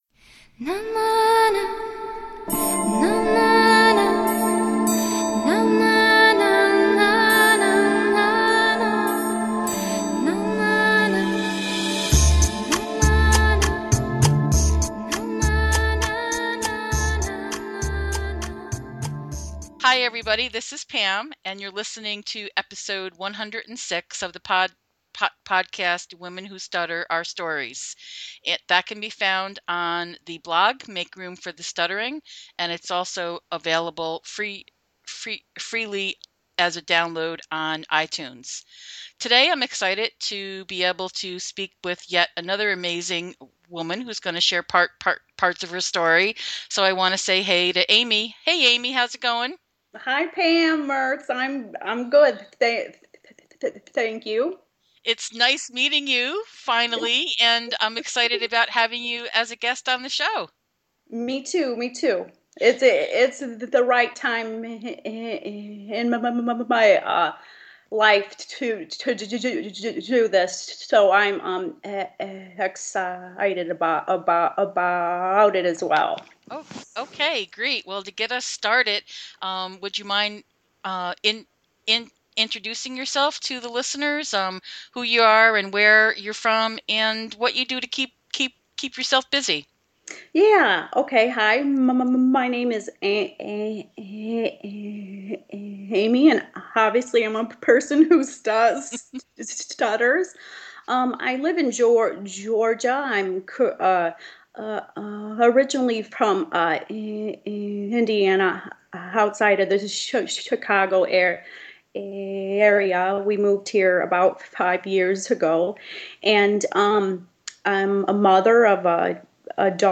This was a great, robust conversation.